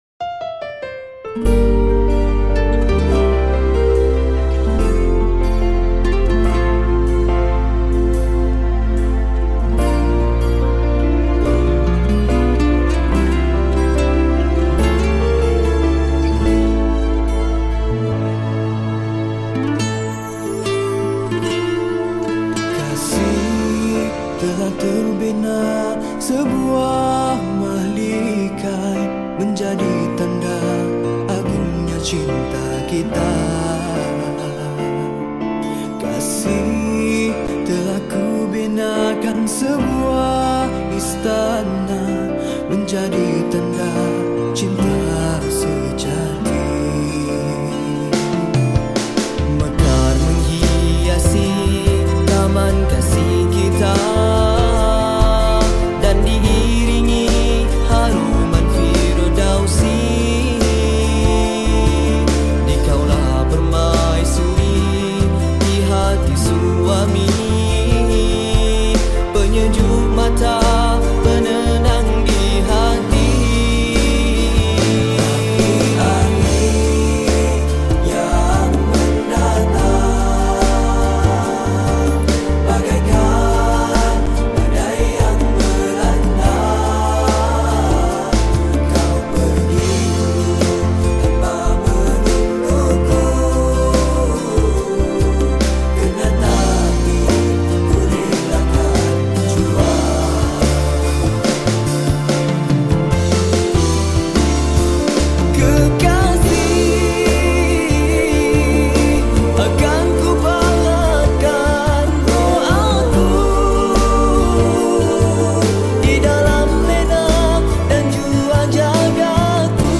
Nasyeed